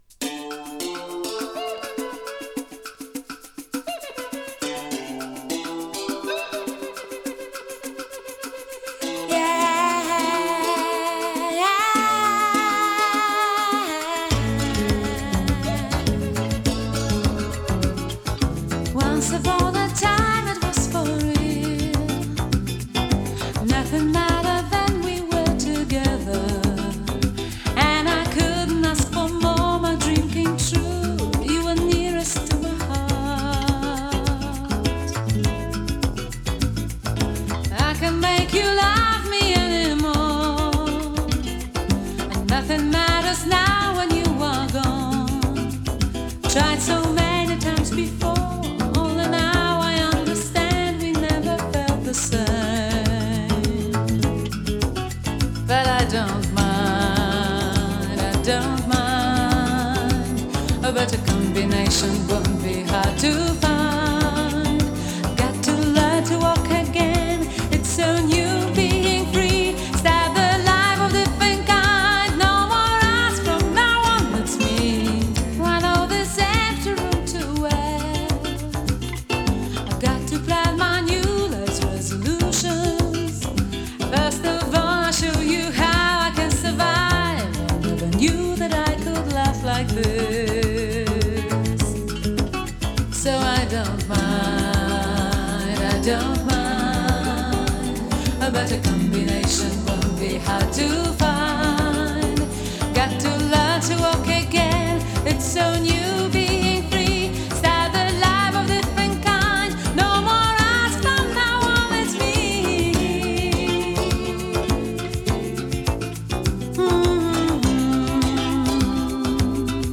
Band Version 4.05